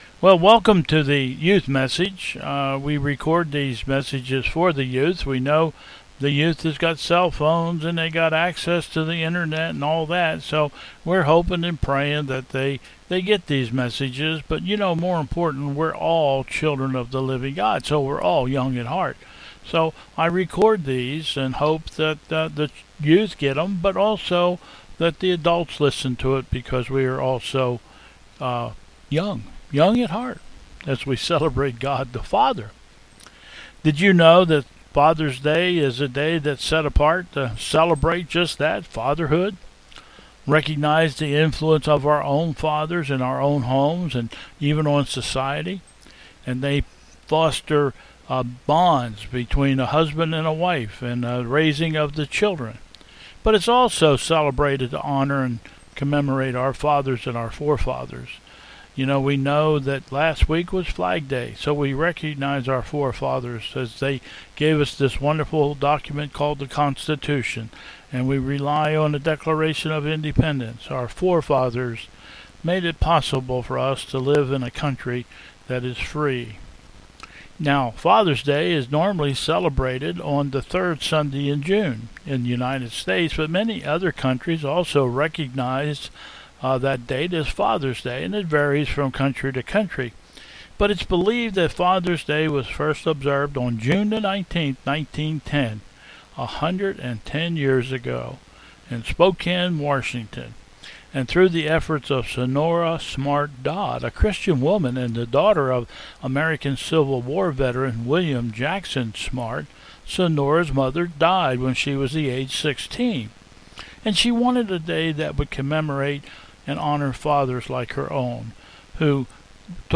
Bethel 06/21/2020 Service
Announcements